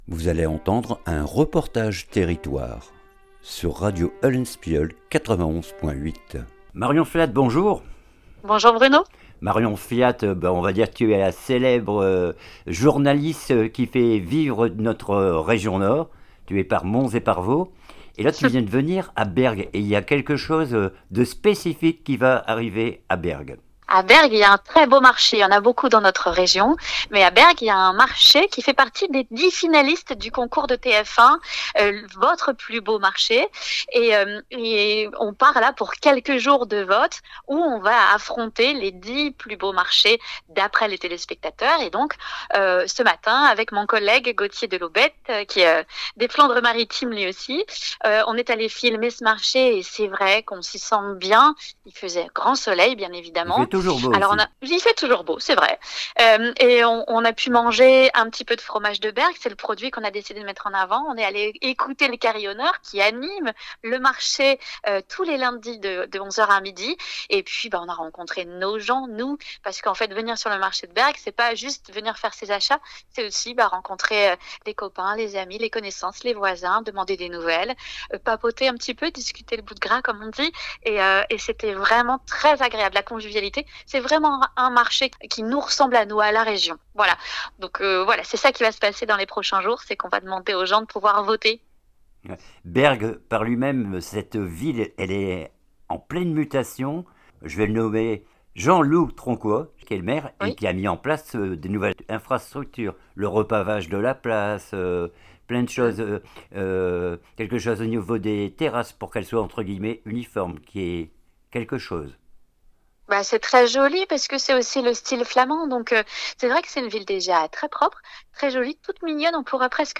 REPORTAGE
ENTRETIEN